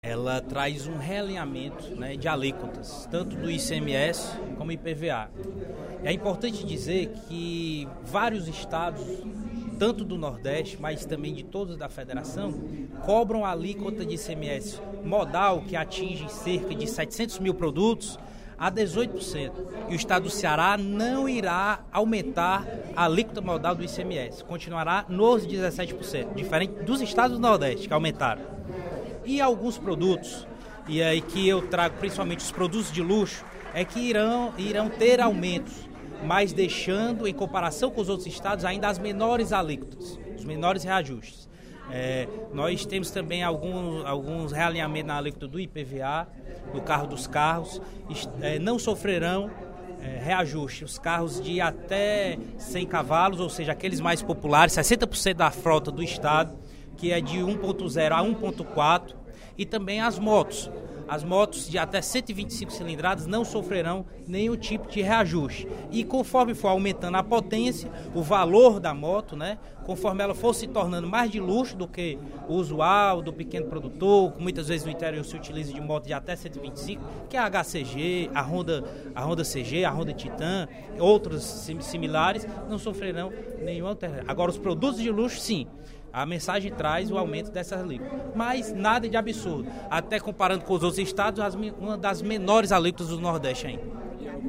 O deputado Júlio César Filho (PTN) defendeu, no primeiro expediente da sessão plenária desta terça-feira (17/11), a mensagem do Executivo que realinha as alíquotas do Imposto sobre Propriedade de Veículos Automotores (IPVA) e do Imposto sobre Circulação de Mercadorias e Serviços (ICMS).